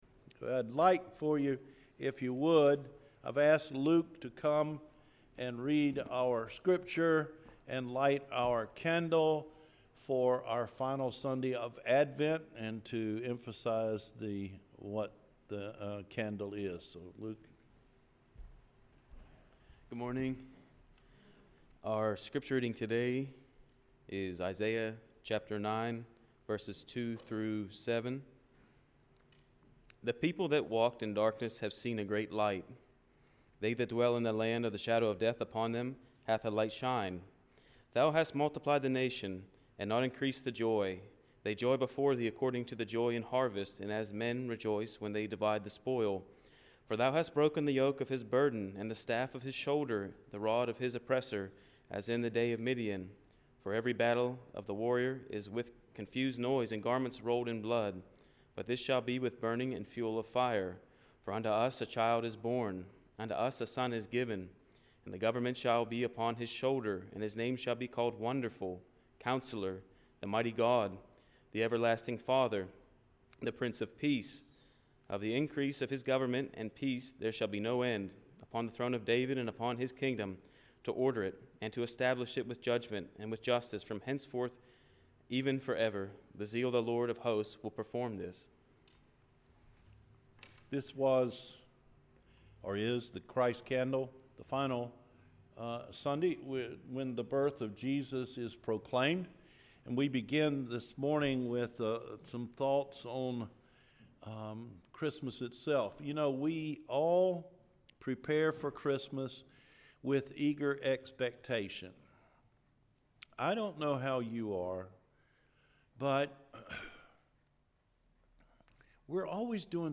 The Reason of the Season – Christmas Eve Service 2017